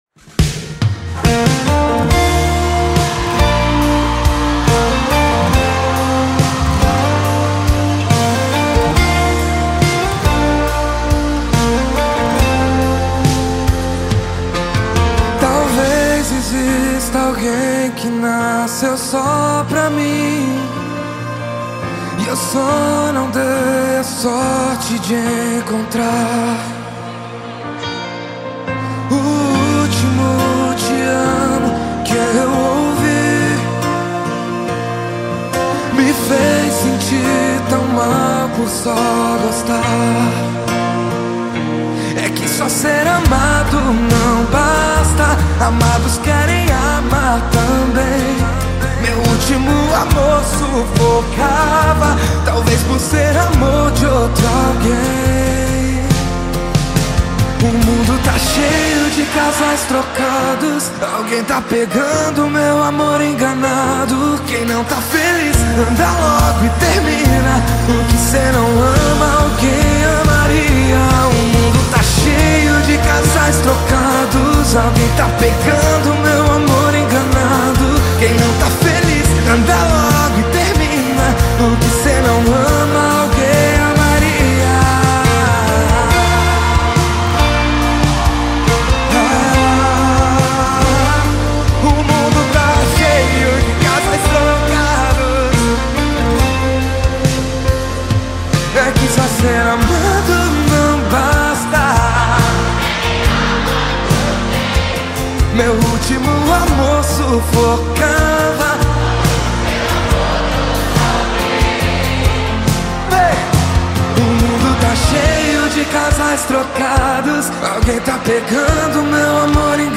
Award winning music composer